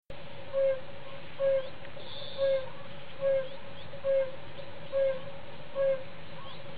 Amphibien-Bombina.mp3